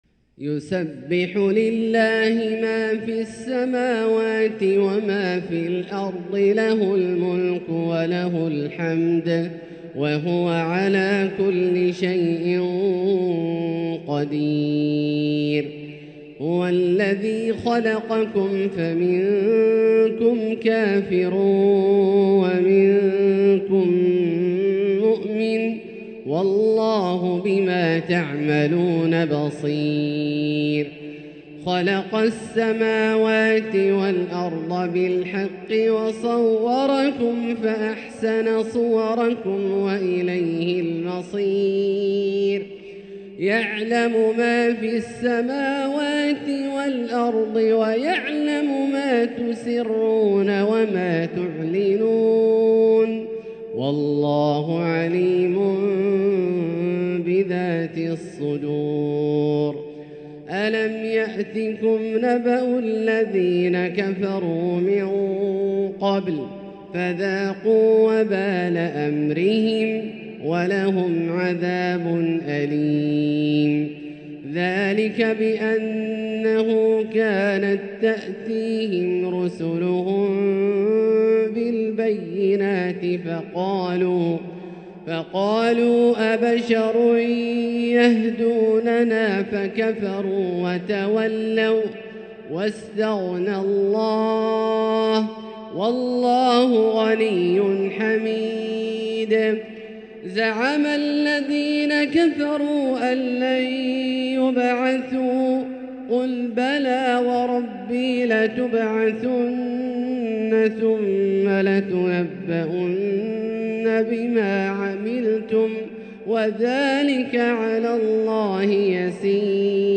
تلاوة ممتعة لـ سورة التغابن كاملة للشيخ أ.د. عبدالله الجهني من المسجد الحرام | Surat At-Taghabun > تصوير مرئي للسور الكاملة من المسجد الحرام 🕋 > المزيد - تلاوات عبدالله الجهني